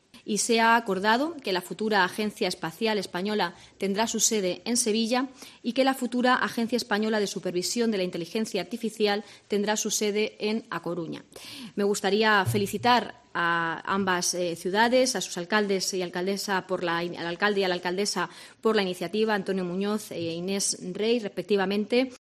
La ministra portavoz anuncia la elección de Sevilla como sede de la AEE